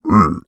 Minecraft Version Minecraft Version 25w18a Latest Release | Latest Snapshot 25w18a / assets / minecraft / sounds / mob / piglin / jealous1.ogg Compare With Compare With Latest Release | Latest Snapshot
jealous1.ogg